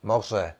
Ääntäminen
Synonyymit océan plan d'eau Ääntäminen France (Paris): IPA: [yn mɛʁ] Tuntematon aksentti: IPA: /mɛʁ/ Haettu sana löytyi näillä lähdekielillä: ranska Käännös Ääninäyte Substantiivit 1. moře {n} Suku: f .